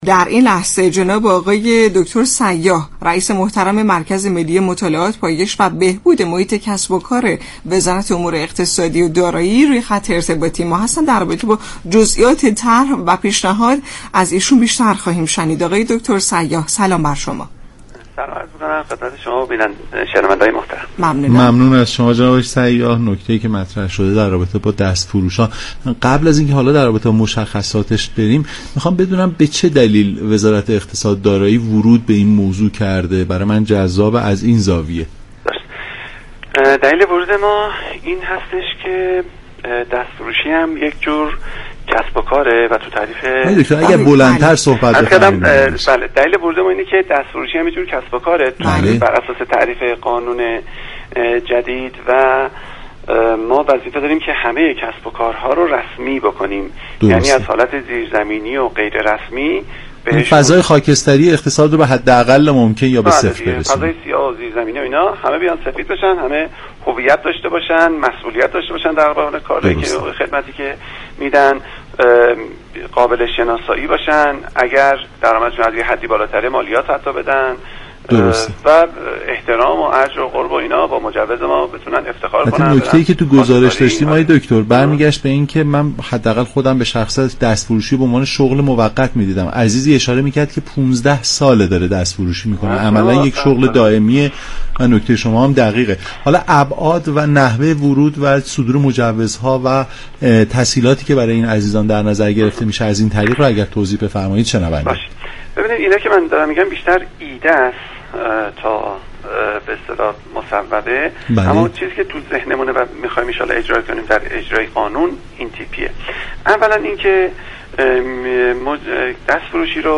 به گزارش شبكه رادیویی ایران، امیر سیاح رییس مركز ملی مطالعات، پایش و بهبود محیط كسب و كار وزارت امور اقتصادی و دارایی در برنامه نمودار درباره طرح پیشنهادی وزارت اقتصاد برای ساماندهی دستفروشان گفت:با توجه به آنكه در قانون جدید دست فروشی نوعی از كسب و كار محسوب می شود دولت موظف به رسمی كردن این نوع از مشاغل است.